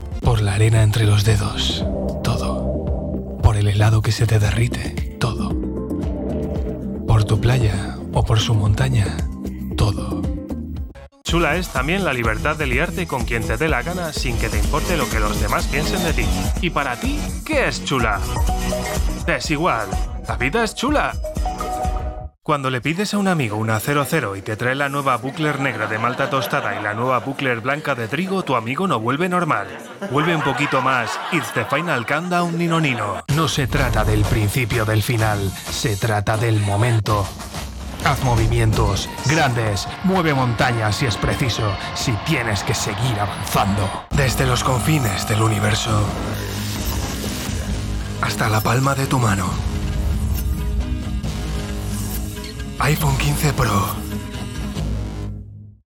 HOMBRES (de 25 a 35 años)